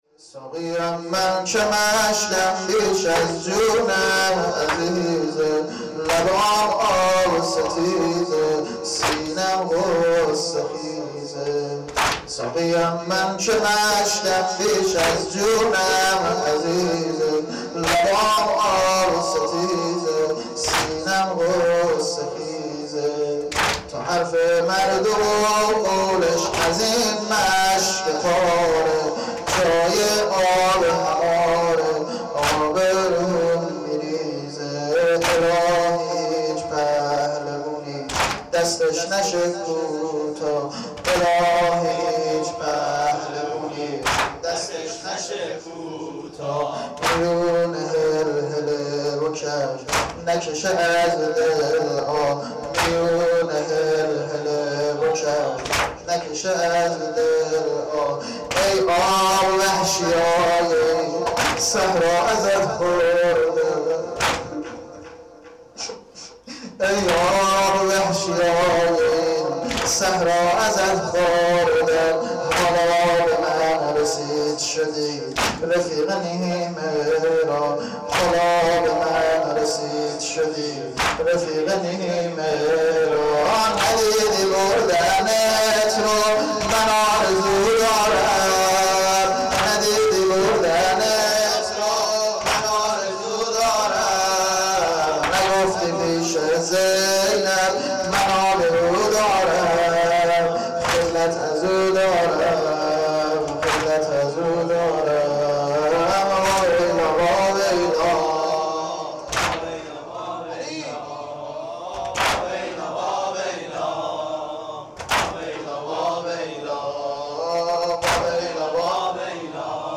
• مراسم سینه زنی شب هفتم محرم هیئت روضه الحسین